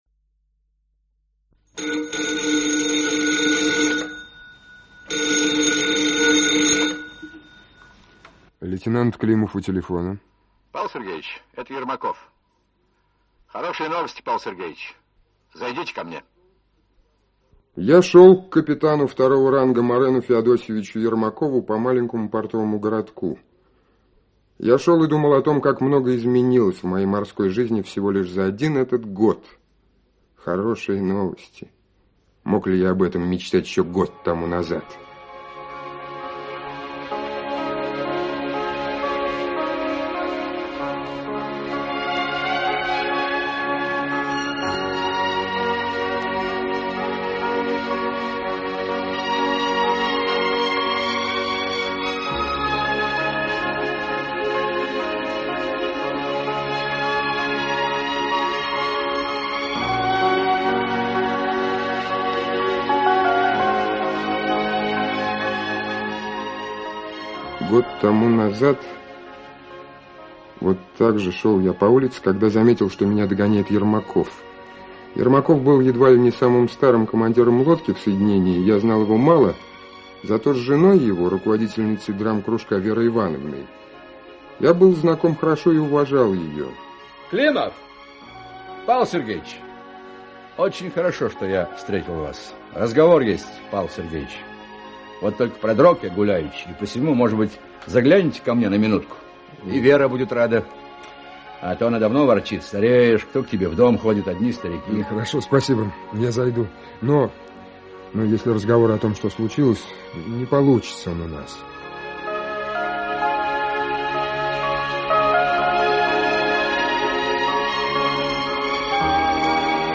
Аудиокнига Дуга большого круга | Библиотека аудиокниг
Aудиокнига Дуга большого круга Автор Леонид Крейн Читает аудиокнигу Актерский коллектив.